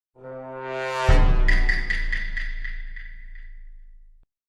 Play, download and share among us role reverb original sound button!!!!
among-us-role-reverb.mp3